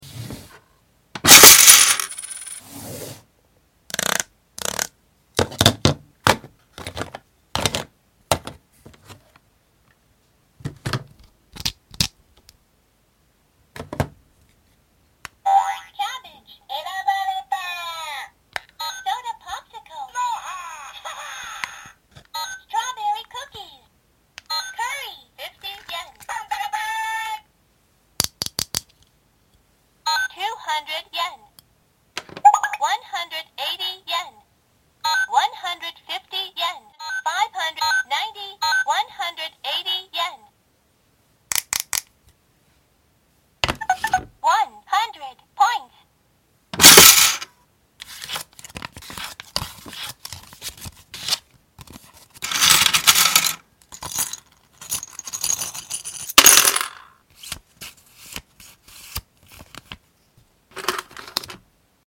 Disney Automatic Scan Cash Register